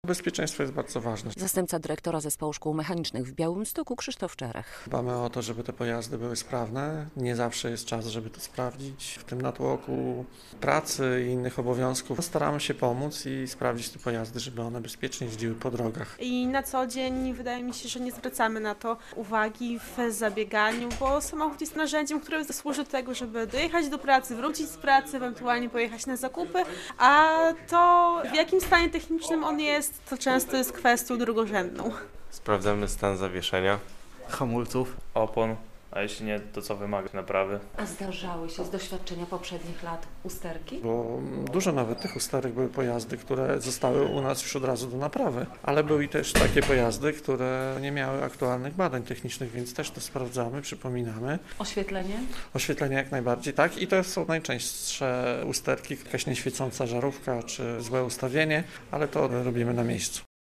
Darmowy przegląd i mycie auta z okazji Dnia Kobiet w białostockim "Mechaniaku" - relacja